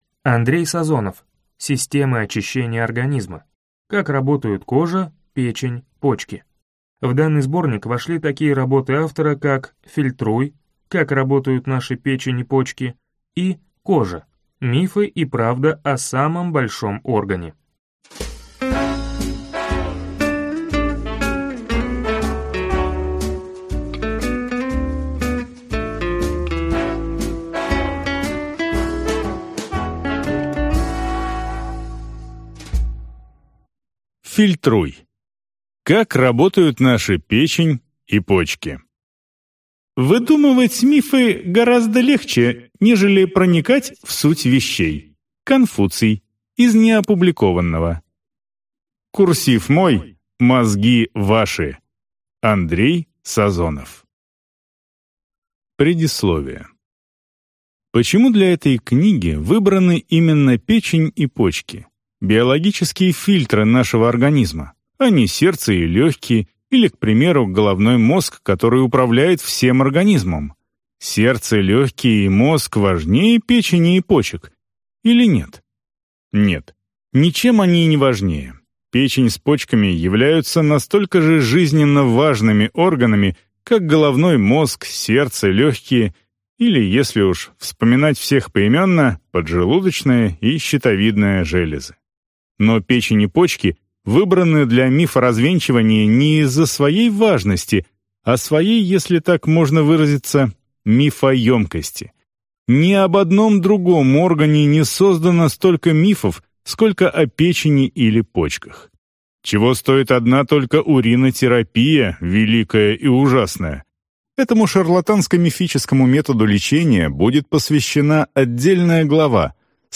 Аудиокнига Системы очищения организма: как работают кожа, печень, почки | Библиотека аудиокниг